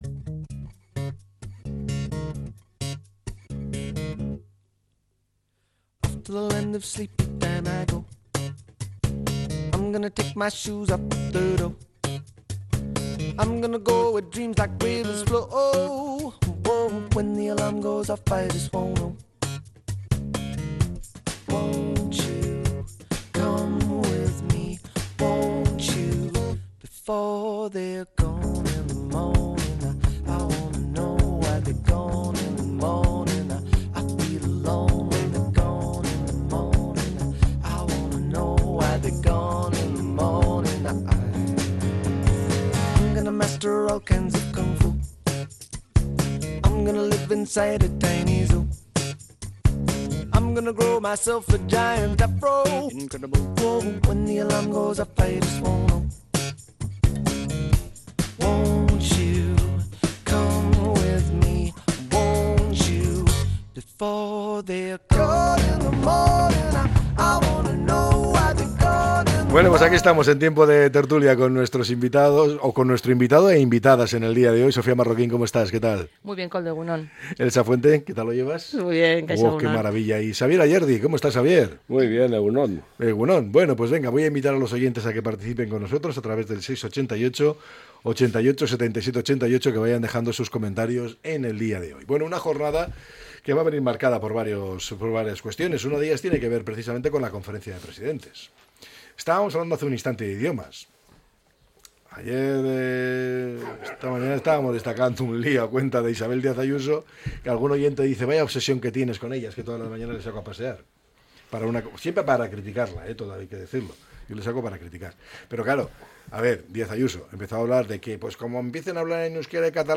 La tertulia 06-06-25.